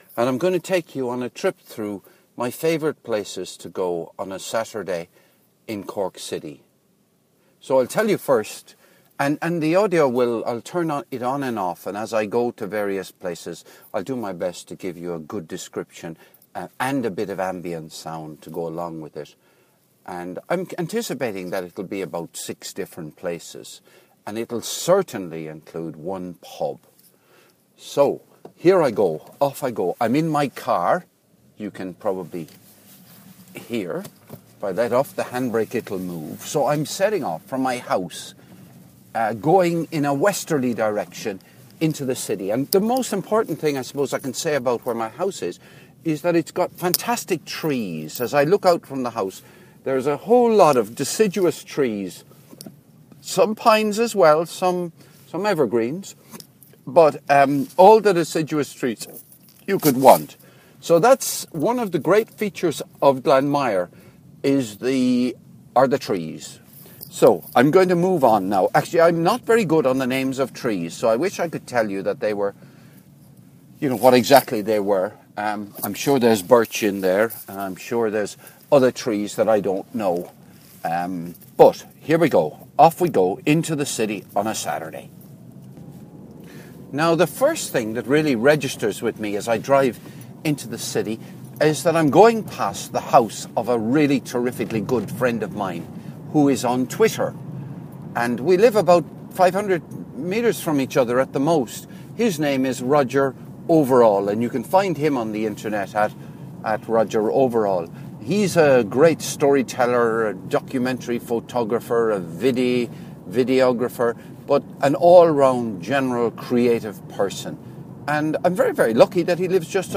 Saturday In Cork - Walking Thru Some Favourite Places (Part 1)
Car journey begins [0.42 - 1.40]